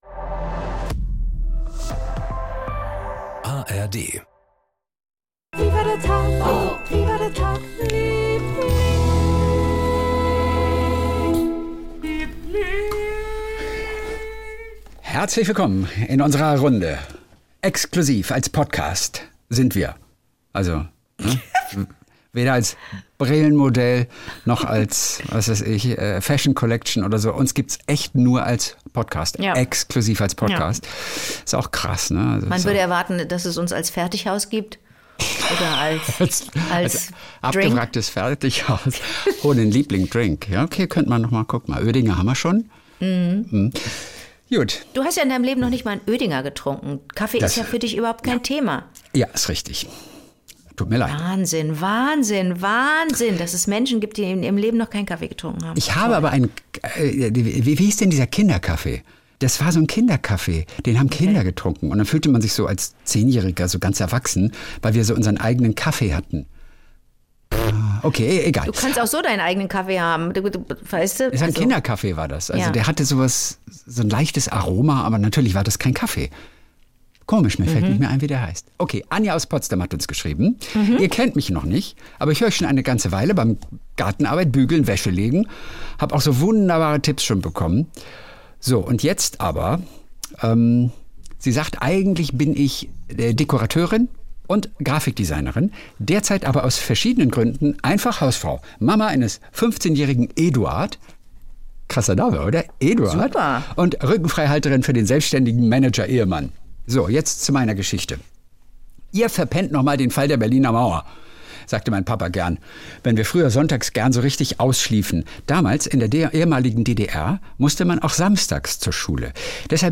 Jeden Montag und Donnerstag Kult: SWR3-Moderator Kristian Thees und seine beste Freundin Anke Engelke erzählen sich gegenseitig ihre kleinen Geschichtchen des Tages.